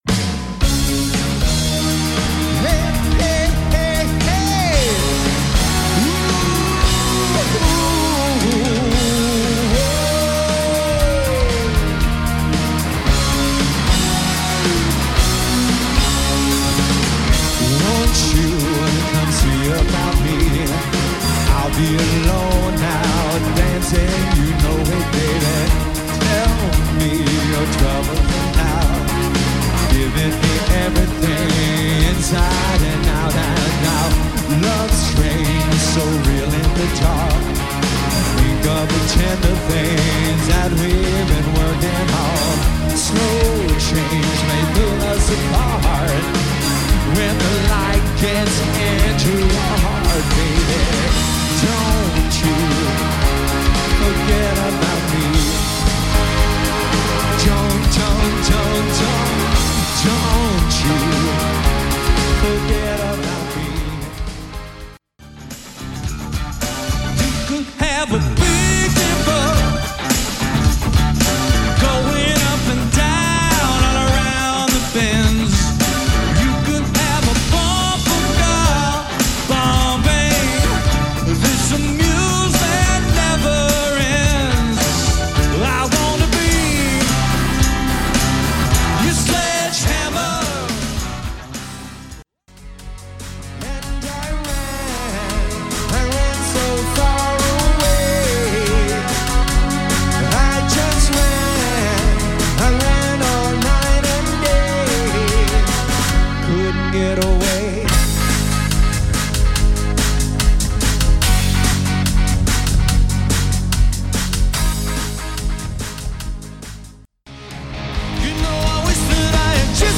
Guitar, Vocals
Keyboards, Percussion, Vocals
Drums, Percussion